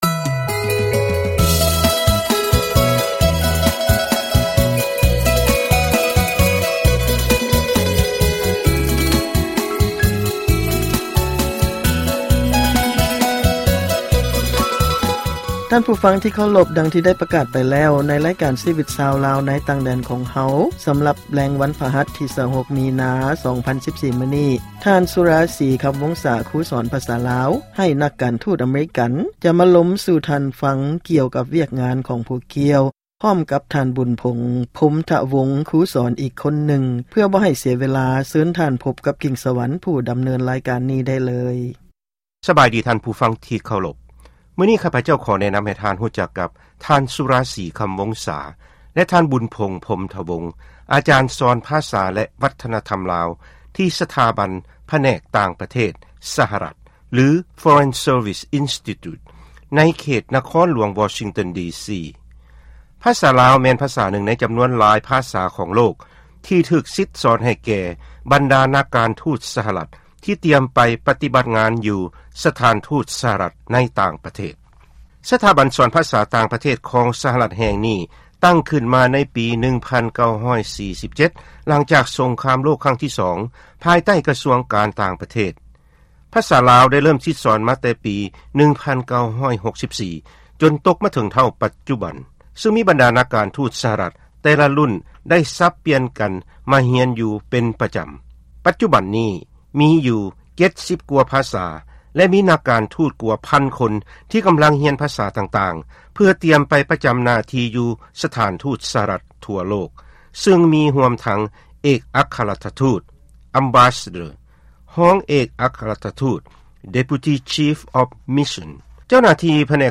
ຟັງການສຳພາດ ອາຈານສອນ ພາສາລາວ